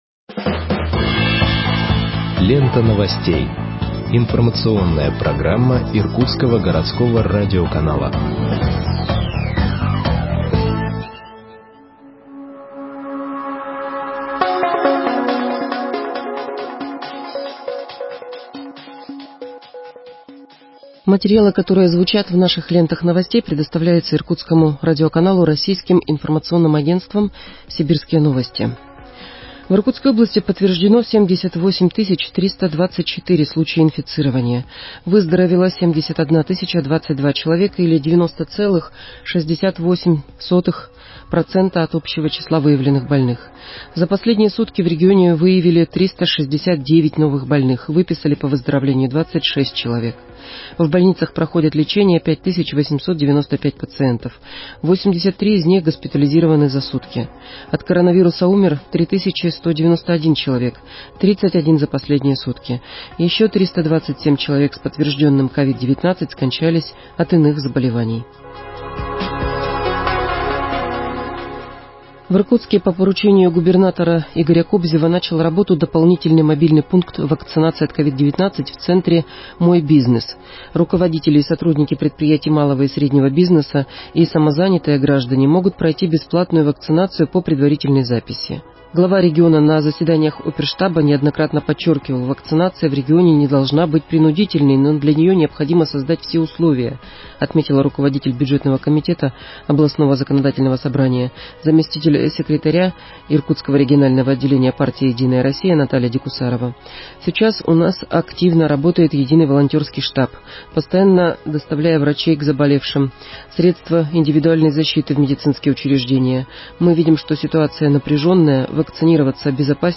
Выпуск новостей в подкастах газеты Иркутск от 12.07.2021 № 2